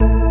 orgTTE54011organ-A.wav